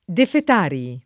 vai all'elenco alfabetico delle voci ingrandisci il carattere 100% rimpicciolisci il carattere stampa invia tramite posta elettronica codividi su Facebook defetari [ defet # ri ] (raro, alla lat., defetarii [ defet # ri-i ]) s. m. pl.